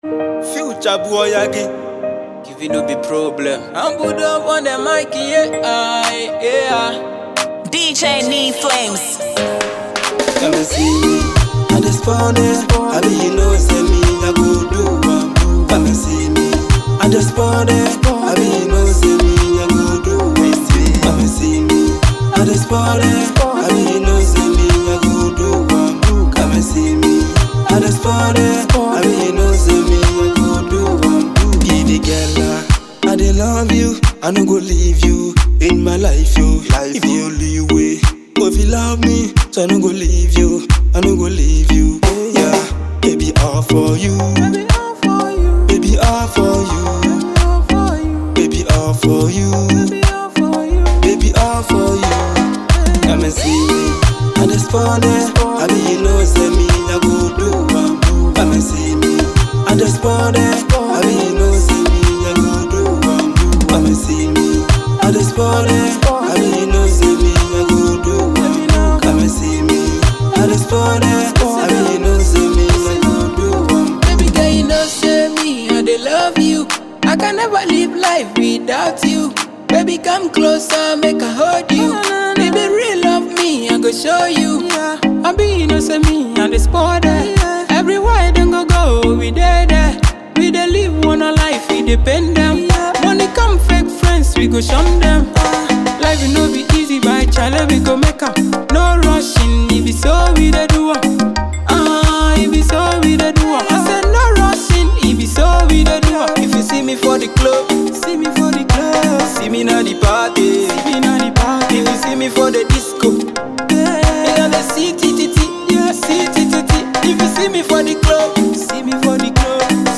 reggae banger